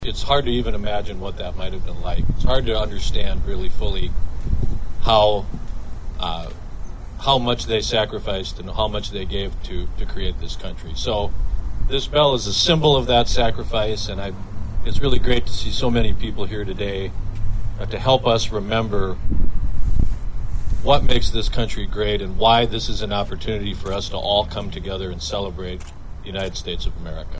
Lieutenant Governor Tony VenHuizen says he read the book 1776 earlier this year and encouraged those watching the bell ceremony to read it too…